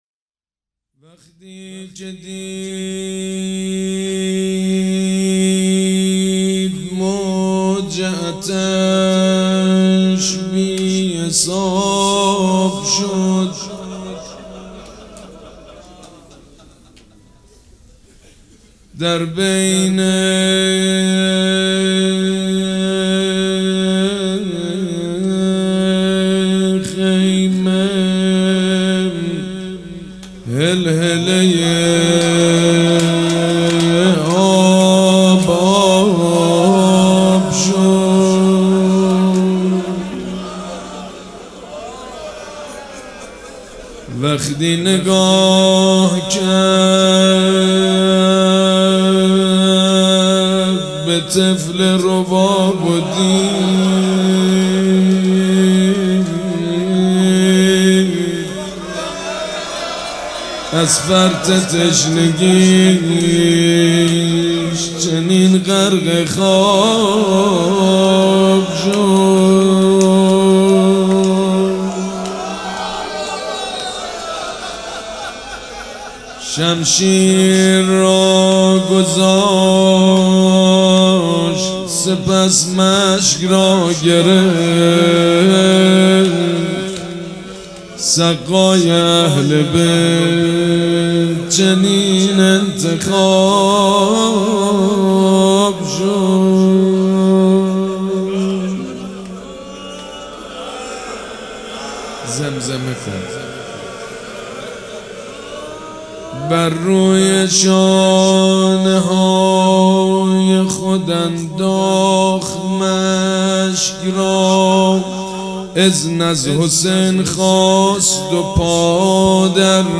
روضه بخش سوم
حاج سید مجید بنی فاطمه جمعه 12 مهر 1398 هیئت ریحانه الحسین سلام الله علیها
سبک اثــر روضه مداح حاج سید مجید بنی فاطمه